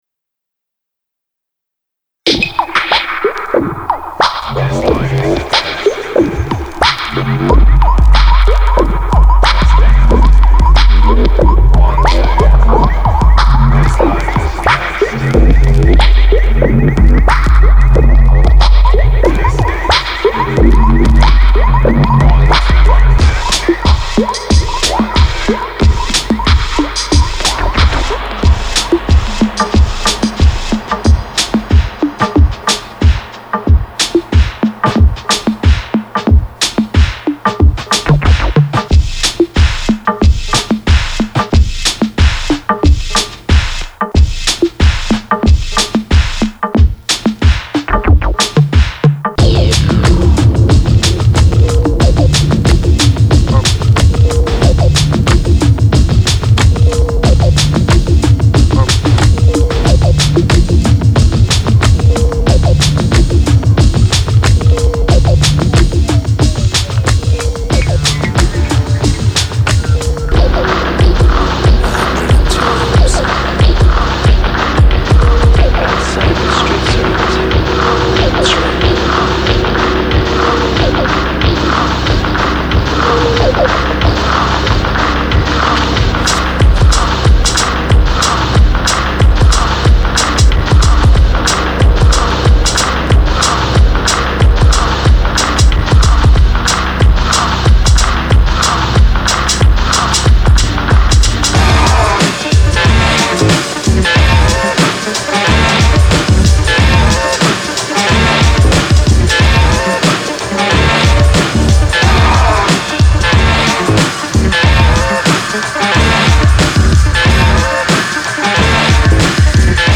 〈試聴〉ダイジェストになります。
ヴァイナルオンリーに焦点を定め
デジタル音源を駆使しての今の気分を充満させ、BPMをアップダウンさせながらボトムをキープ